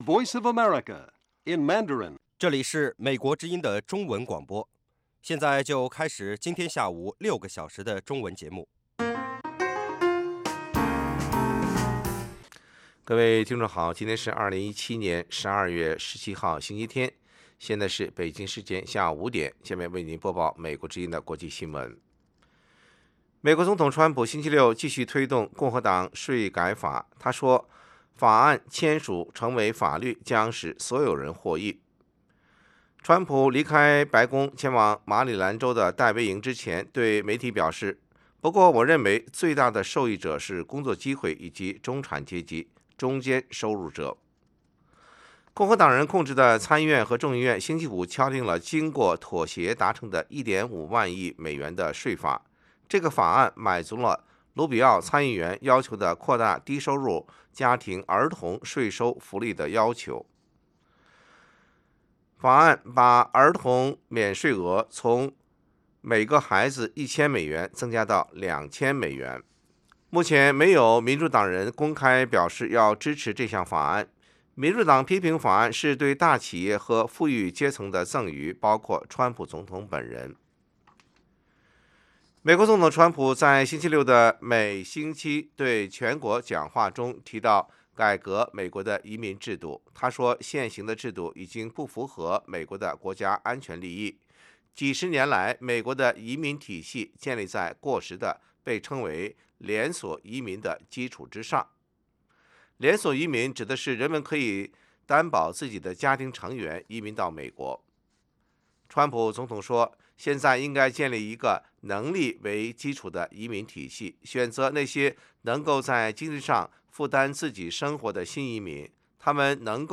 北京时间下午5-6点广播节目。广播内容包括国际新闻，美语训练班(学个词， 美国习惯用语，美语怎么说，英语三级跳， 礼节美语以及体育美语)，以及《时事大家谈》(重播)